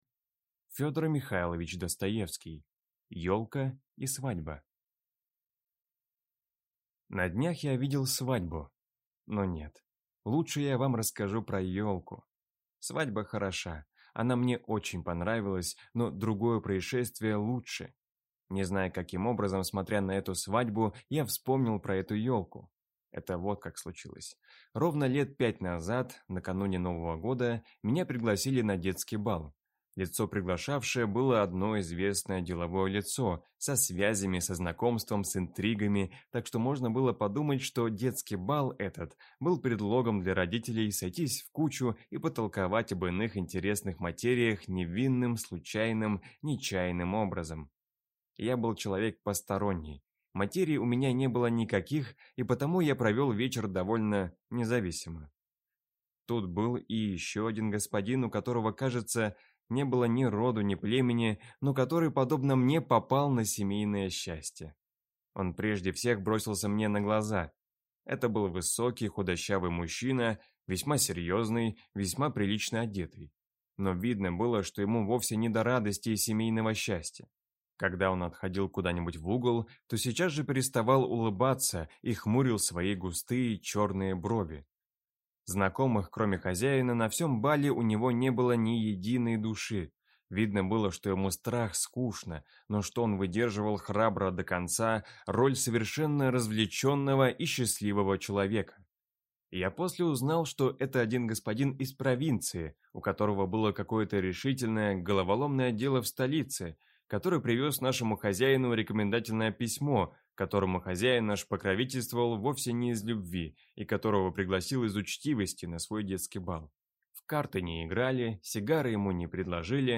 Аудиокнига Елка и свадьба | Библиотека аудиокниг
Прослушать и бесплатно скачать фрагмент аудиокниги